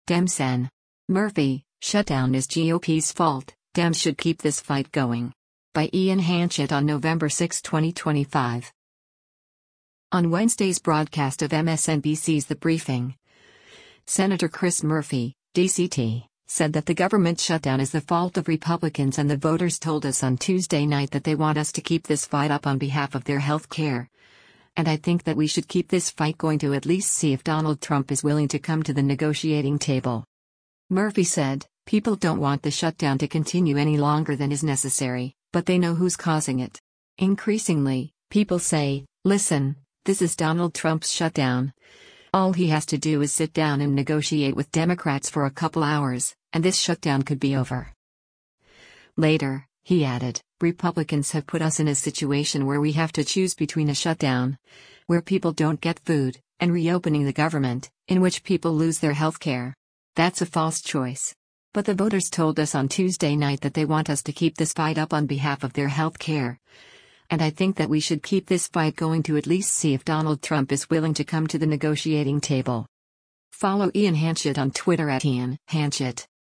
On Wednesday’s broadcast of MSNBC’s “The Briefing,” Sen. Chris Murphy (D-CT) said that the government shutdown is the fault of Republicans and “the voters told us on Tuesday night that they want us to keep this fight up on behalf of their health care, and I think that we should keep this fight going to at least see if Donald Trump is willing to come to the negotiating table.”